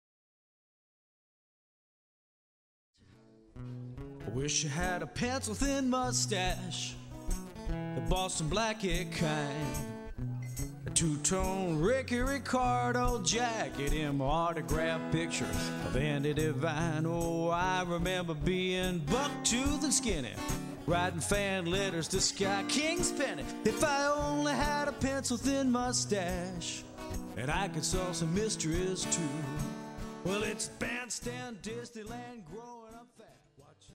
Solo - Live Demos
Live Demos